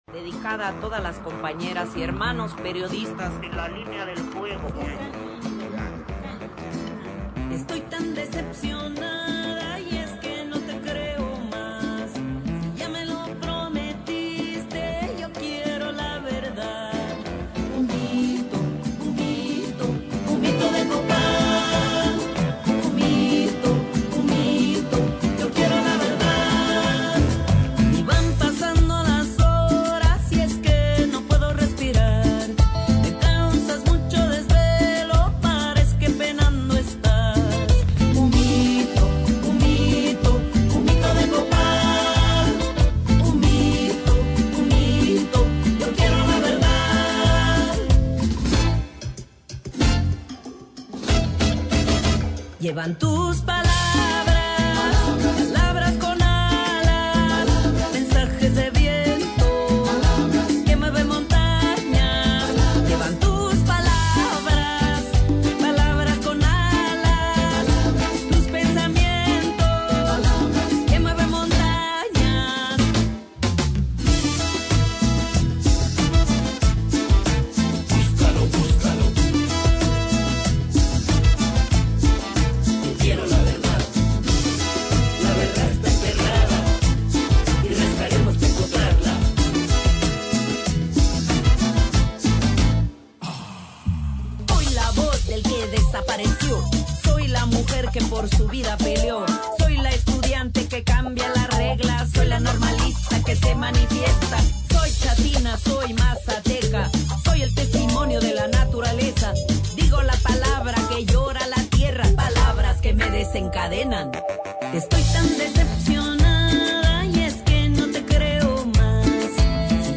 Conversamos con la compositora, intérprete y productora mexicana que nos visitará el próximo 29 de agosto en el Auditorio Nacional del Sodre.